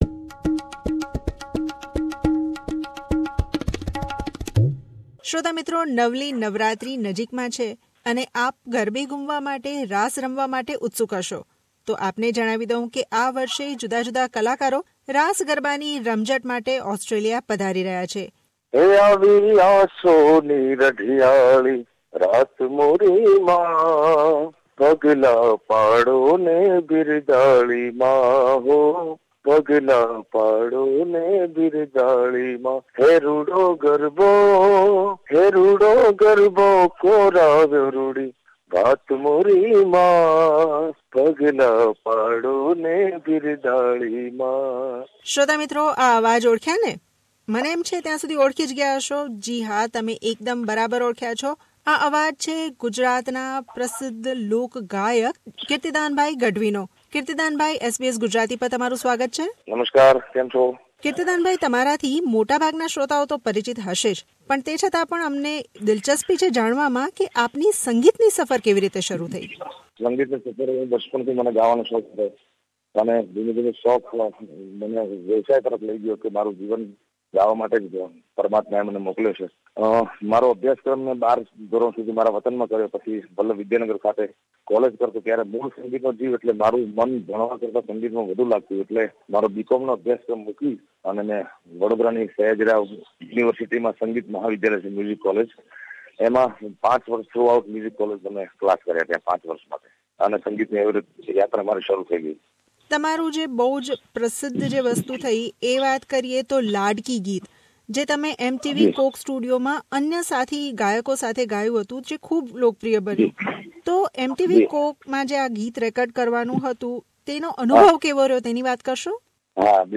Kirtidan Gadhvi is the first and only singer from Gujarat who was nominated for two songs in Oscar Awards.